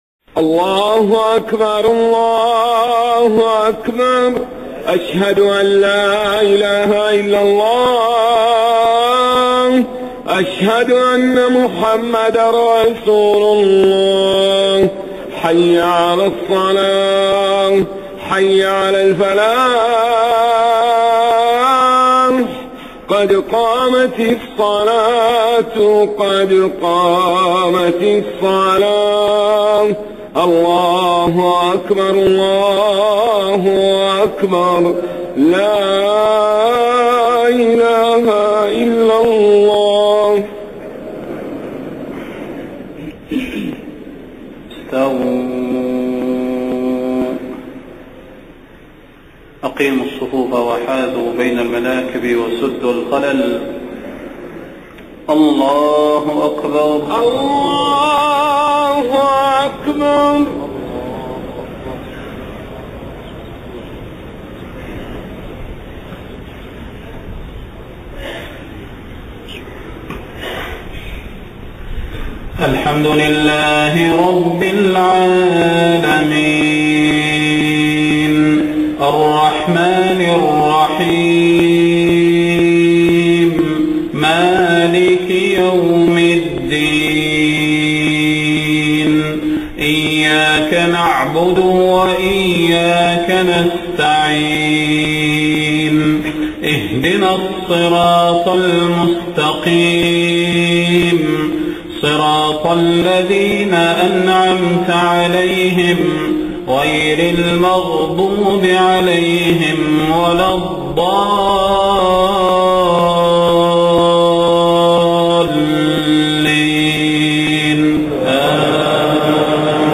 صلاة المغرب 2 صفر 1430هـ سورتي العاديات والقارعة > 1430 🕌 > الفروض - تلاوات الحرمين